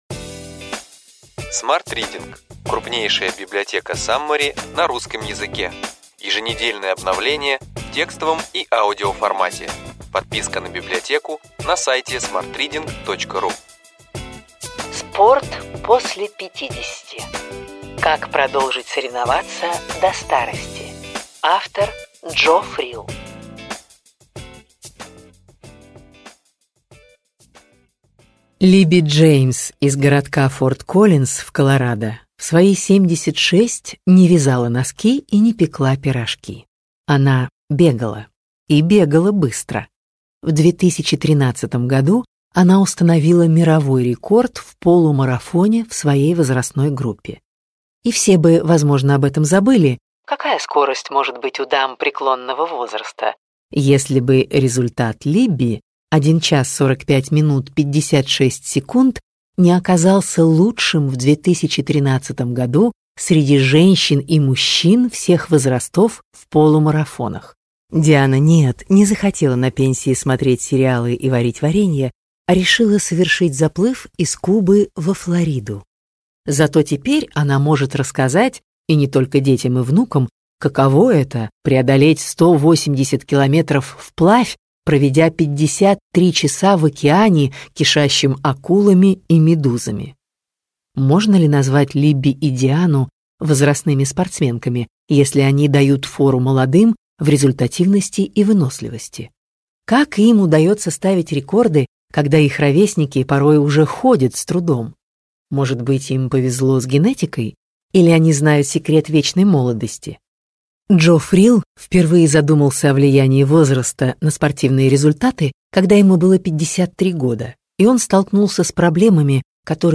Аудиокнига Ключевые идеи книги: Спорт после 50. Как продолжить соревноваться до старости.